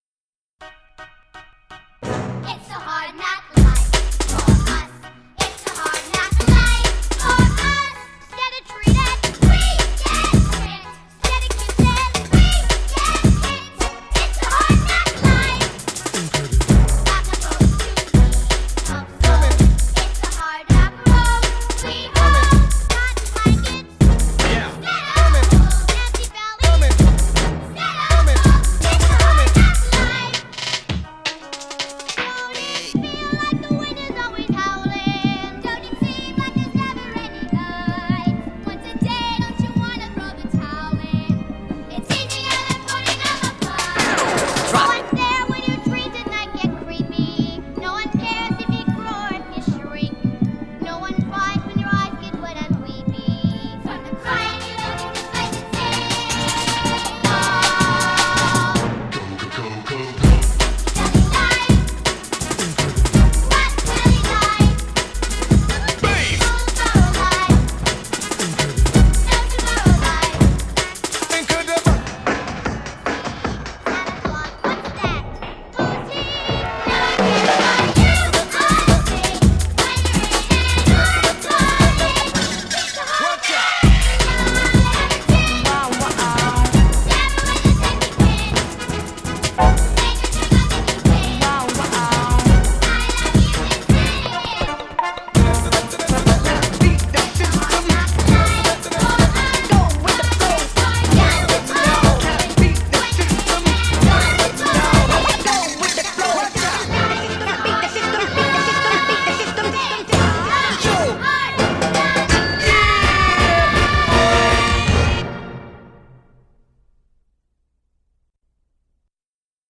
mashed-up with drum & bass embellishments.